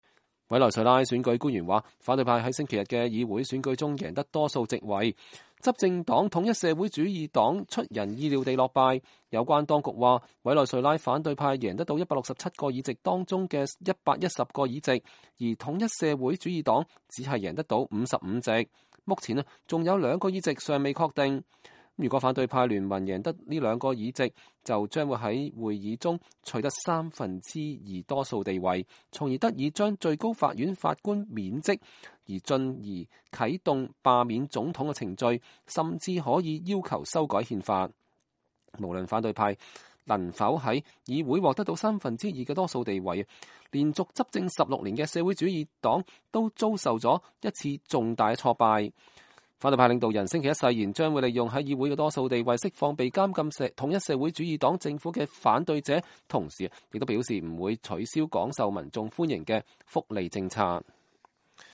反對派支持者慶祝勝利